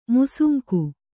♪ musunku